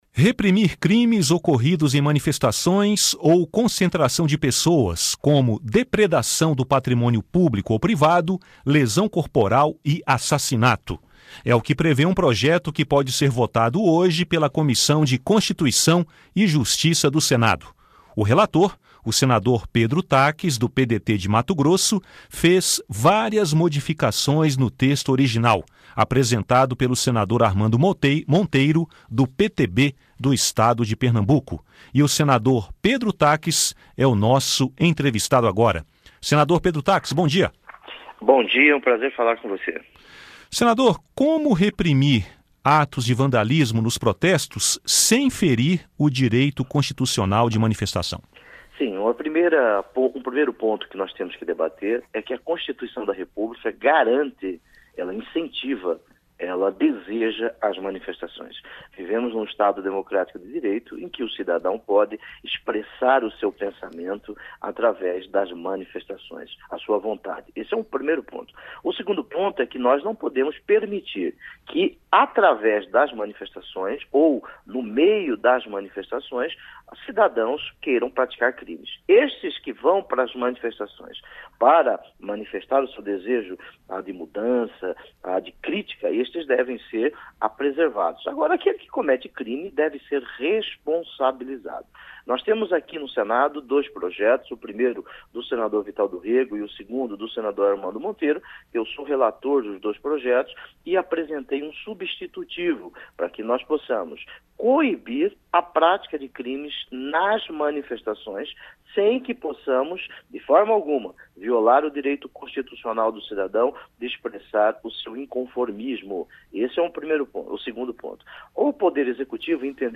CCJ pode votar projeto que criminaliza vandalismo em manifestações populares Entrevista com o relator do projeto, senador Pedro Taques (PDT-MT).